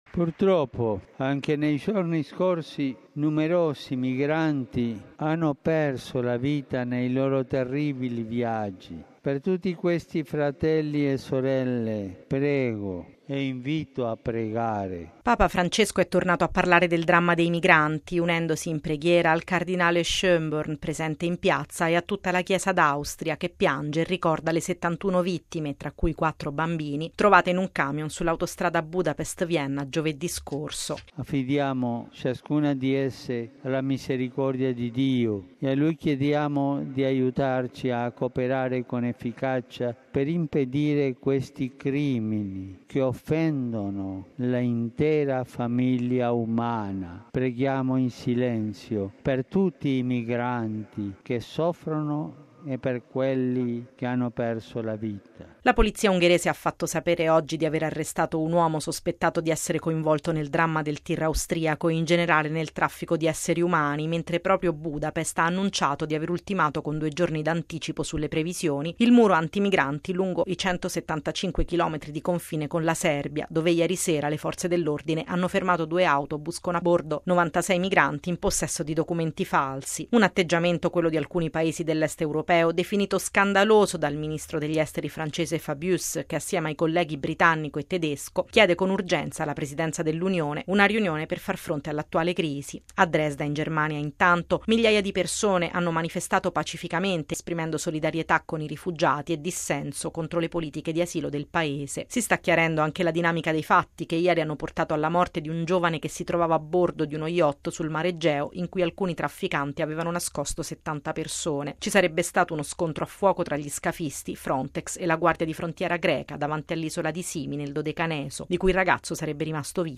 Un appello all’umanità contro le continue stragi di migranti: così Papa Francesco, all’Angelus di oggi in piazza San Pietro, ha ricordato un dramma che si consuma ogni giorno sotto gli occhi di tutti.